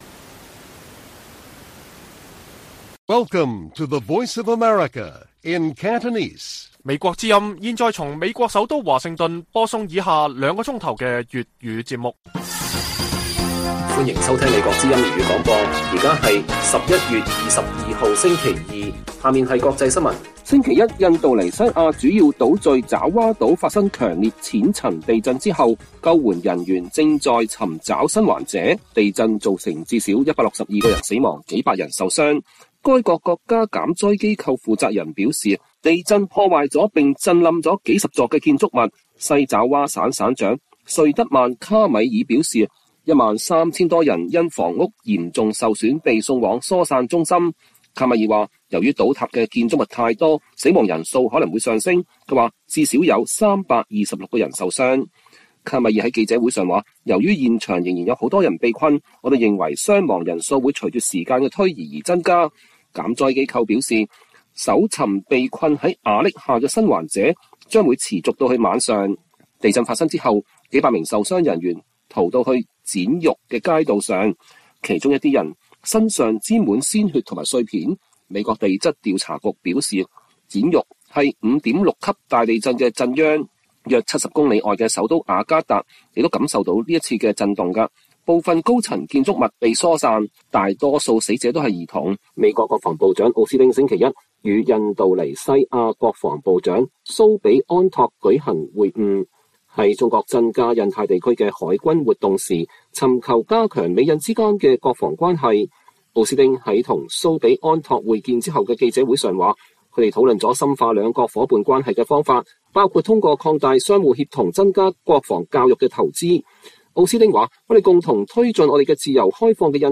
粵語新聞 晚上9-10點: 印尼地震至少162人死亡